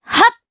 Laudia-Vox_Attack1_jp.wav